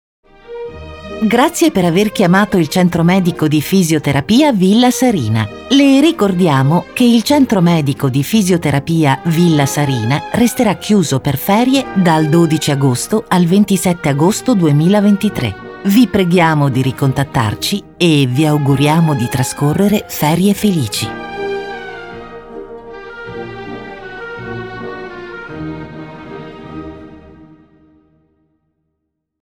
Natural, Versátil, Seguro, Maduro, Suave
Telefonía